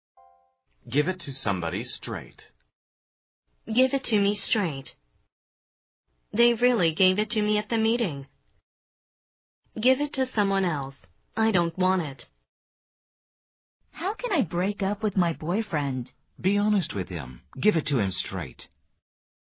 通过生动的对话说明短语的实际表达用法，8000多句最实用的经典表达，保证让你讲出一口流利又通顺的英语，和老外聊天时再也不用担心自己的英语错误百出了！